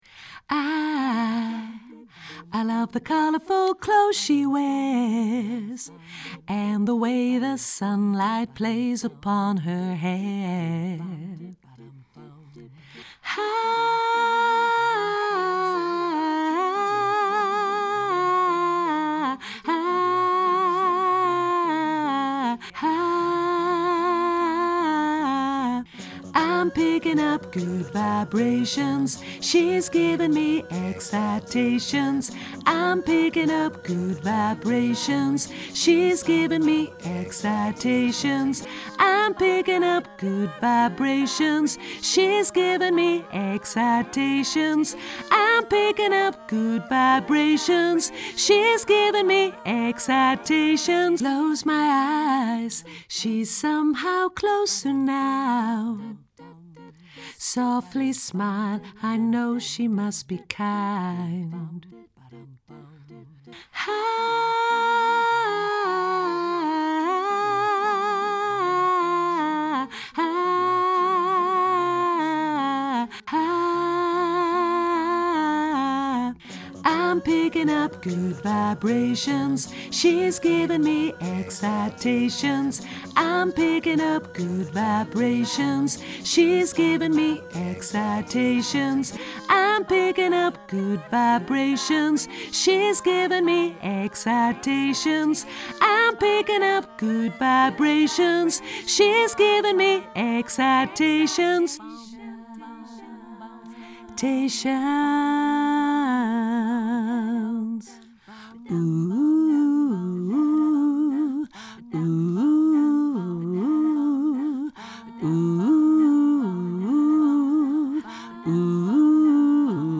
alt hoog